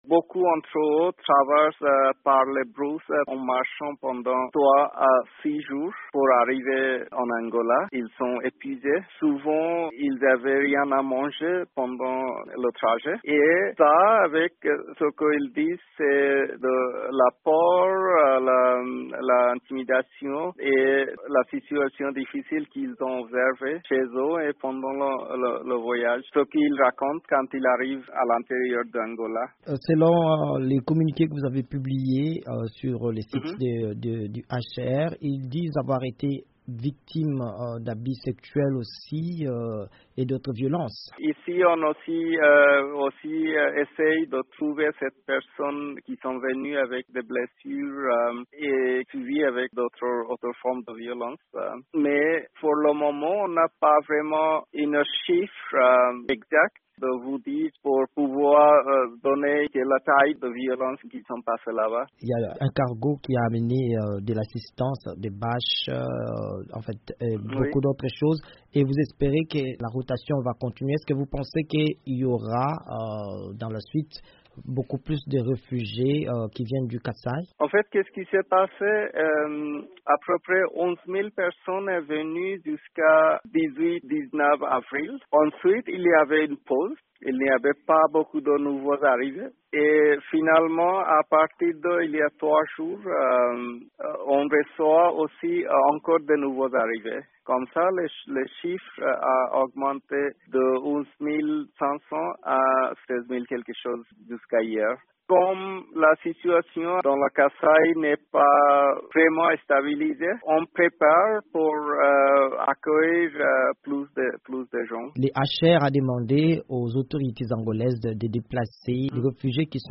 indique dans une interview à VOA Afrique